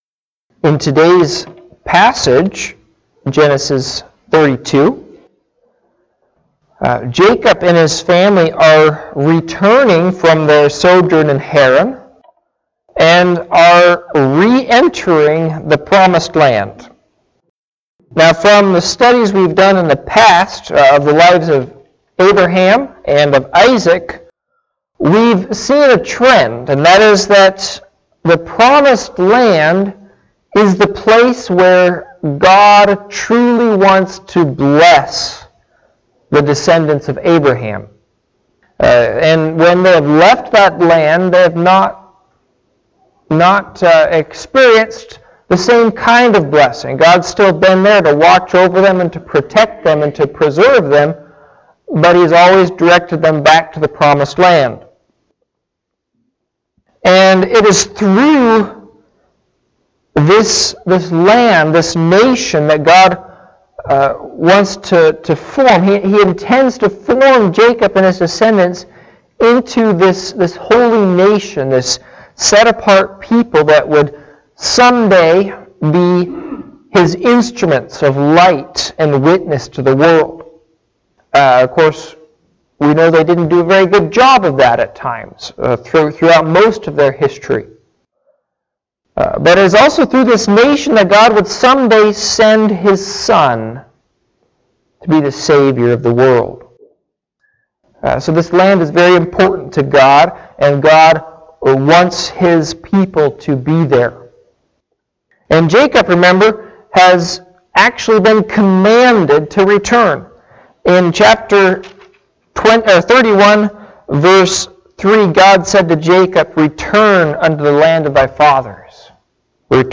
Service Type: Morning Sevice